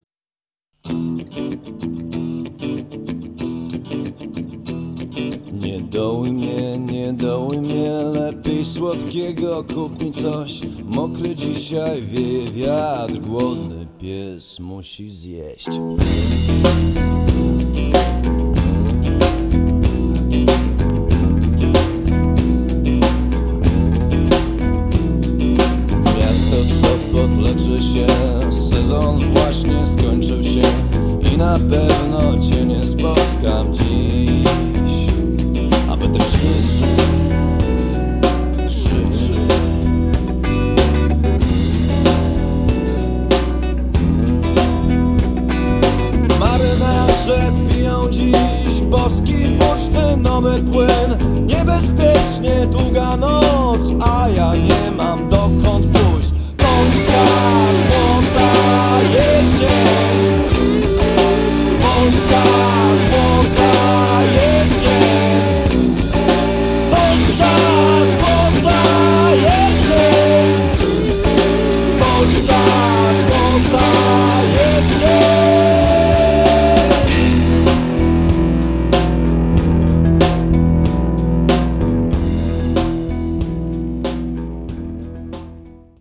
gitarka
Jest w tej muzyce coś z charakteru rockowej piosenki